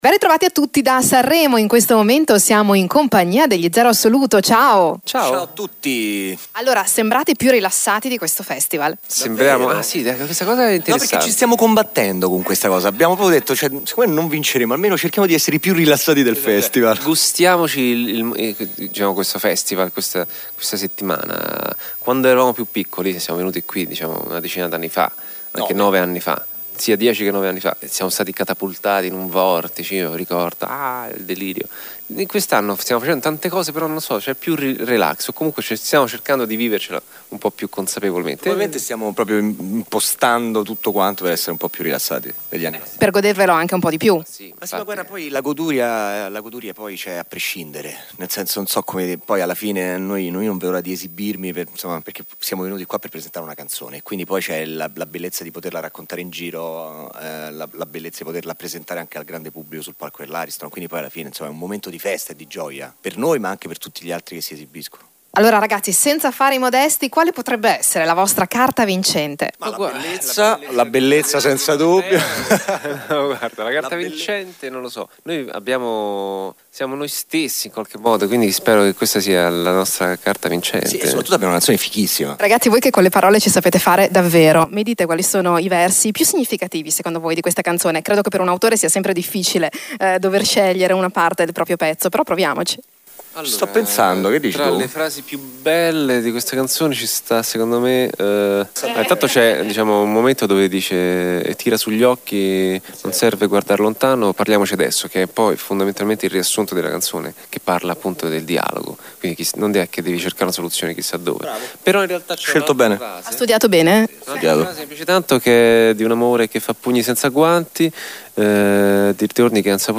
RADIO PICO A SANREMO: INTERVISTA AGLI ZERO ASSOLUTO
intervista-zero-assoluto-edit.mp3